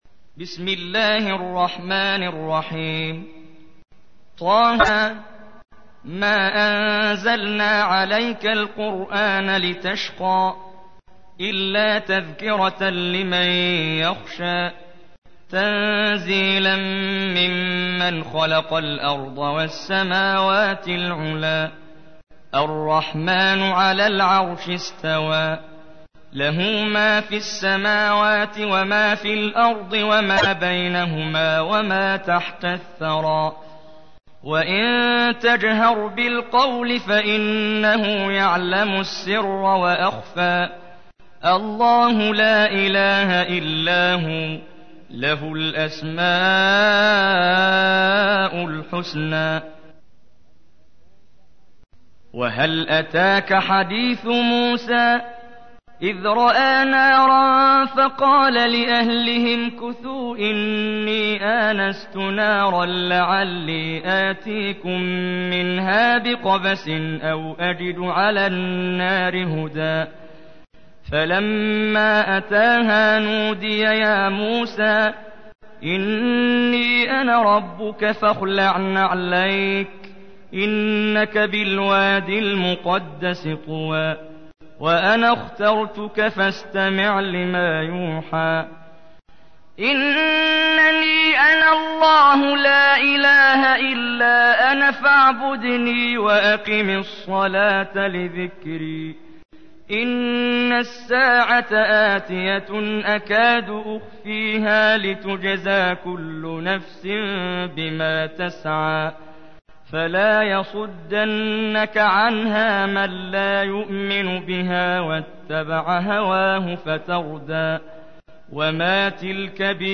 تحميل : 20. سورة طه / القارئ محمد جبريل / القرآن الكريم / موقع يا حسين